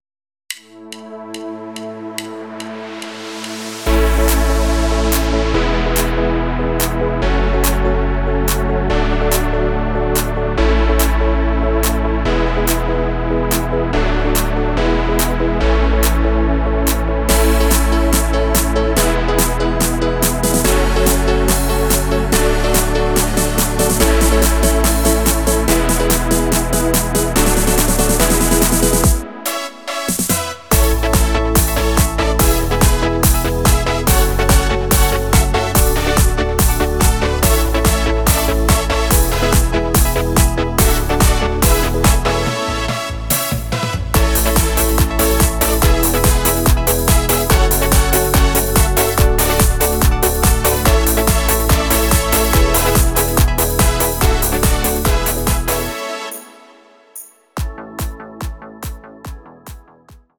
Rhythmus  Disco